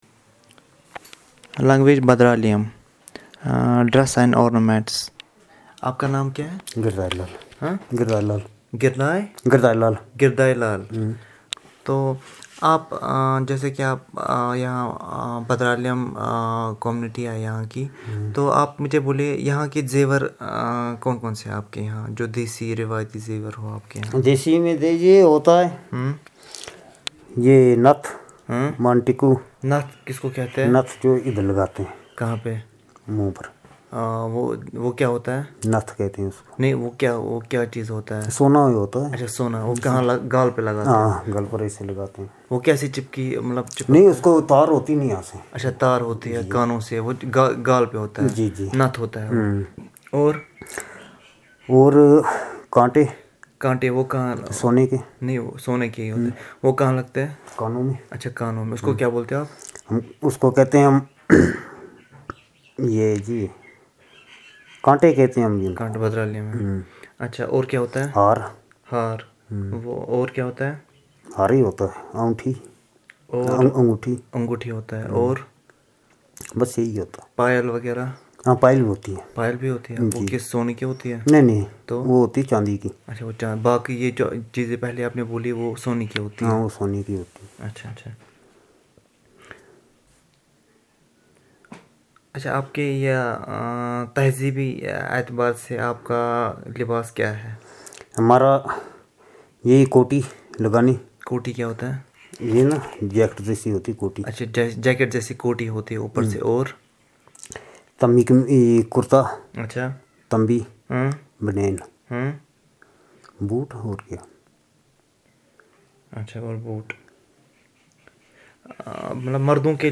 Elicitation of words about clothing and ornament